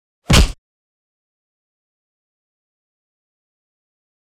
赤手空拳击中肉体11-YS070524.wav
通用动作/01人物/03武术动作类/空拳打斗/赤手空拳击中肉体11-YS070524.wav
• 声道 立體聲 (2ch)